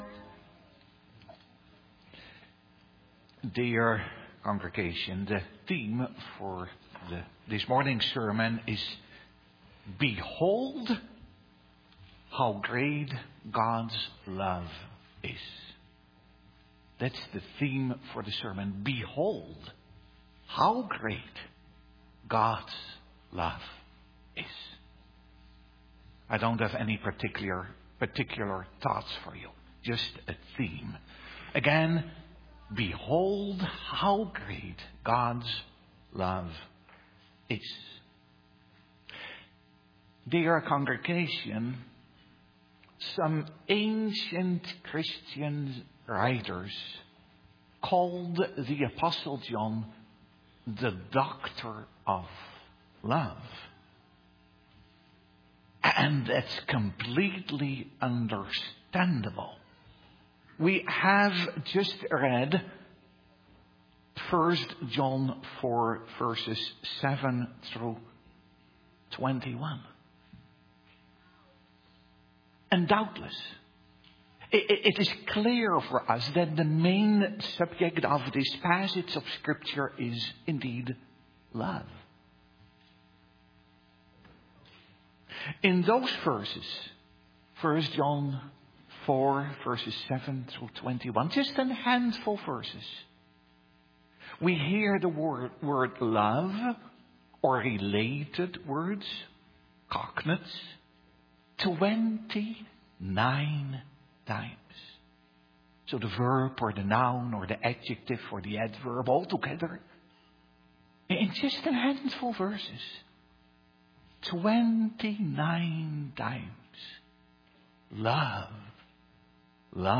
Sermon Audio | Providence Reformed Church
Download Download Reference Sunday Morning Service - Lord's Supper Scripture: 1 John 4:7-21.